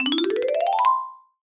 comedy_marimba_ascend_003